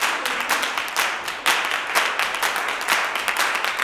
125GCLAPS2-R.wav